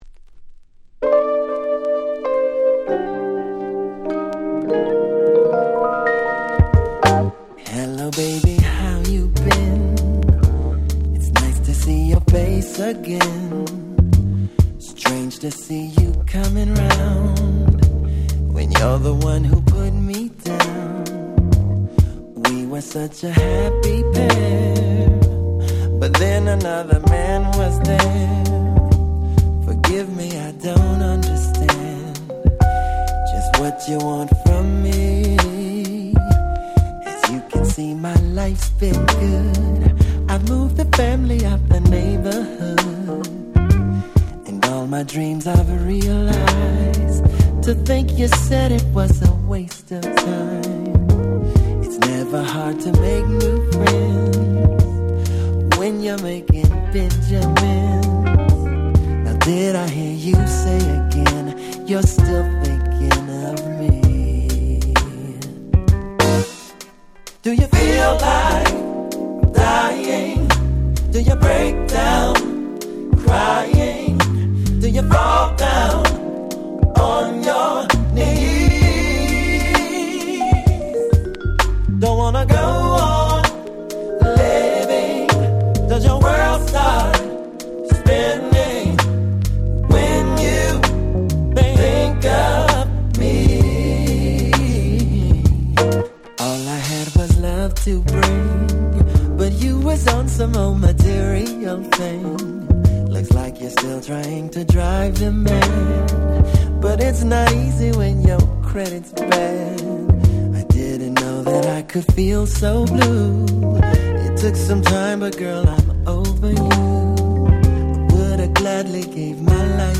99' Very Nice Slow Jam / Neo Soul !!
もう最高の大人のSlowです！！
スロウジャム バラード